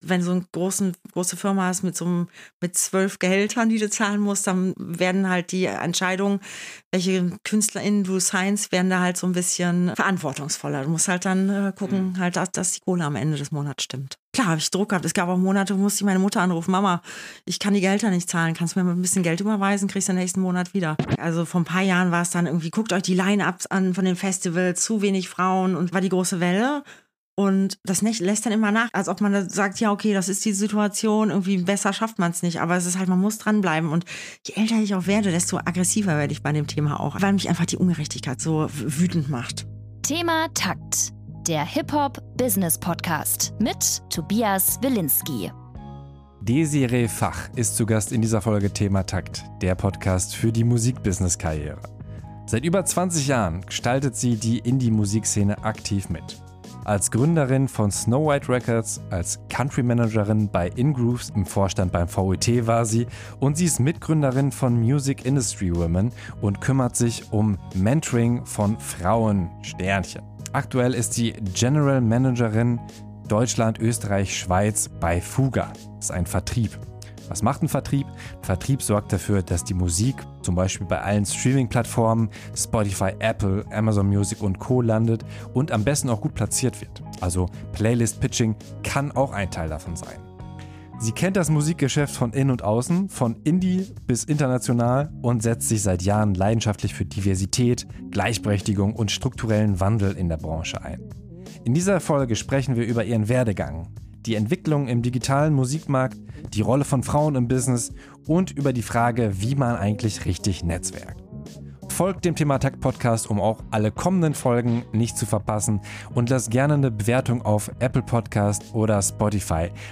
im Interview ~ ThemaTakt - HipHop- & Musikbusiness-Podcast Podcast